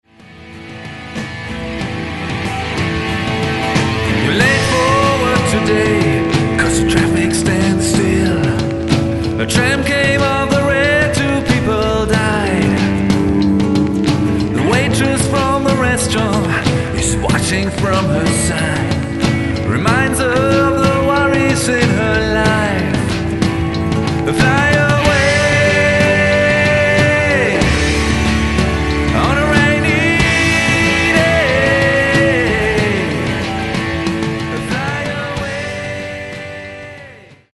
Gesang
Drums
Bass
Keys
Gitarre